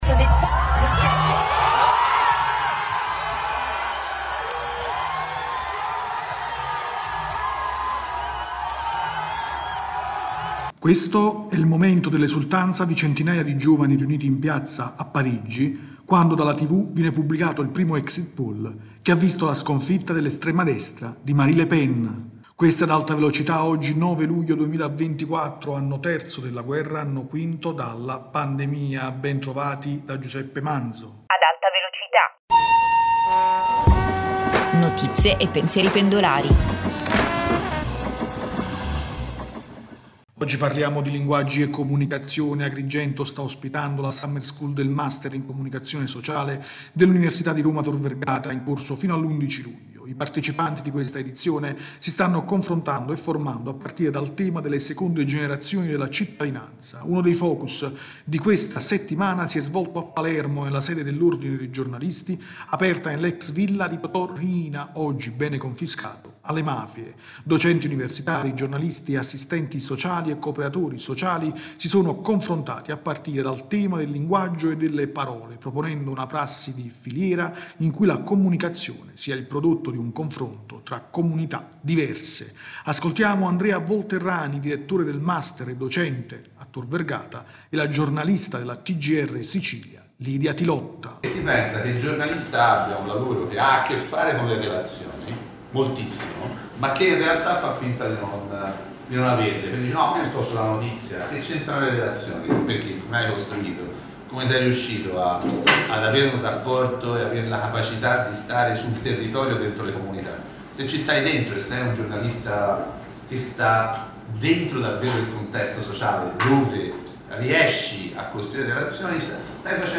rubrica quotidiana